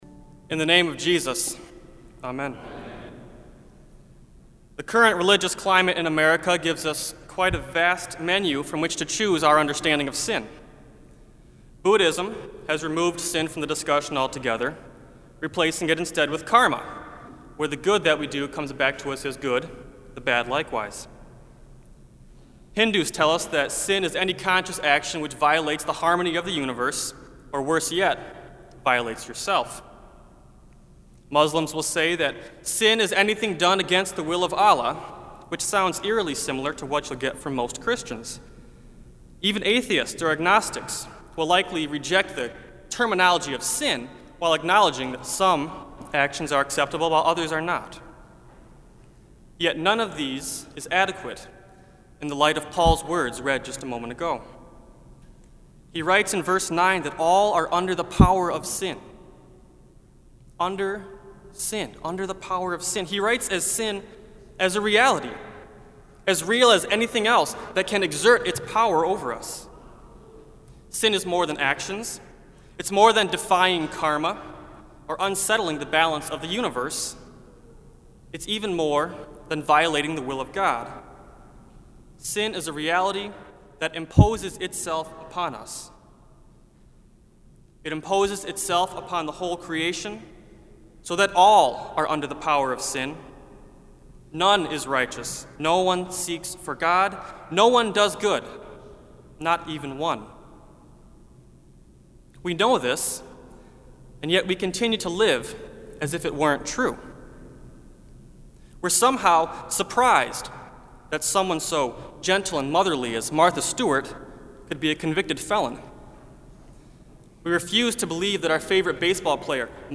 Kramer Chapel Sermon - May 29, 2008